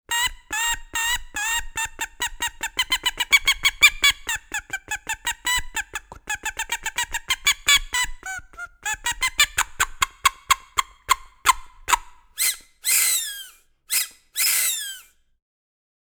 Čivink
Med dva koščka lesa so včasih napeli travo, trak koruznega slačka, češnjev ali brezov lub (kasneje pa umetne materiale).